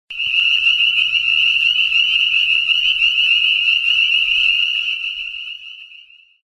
Spring Peepers
spring-peepers-2.mp3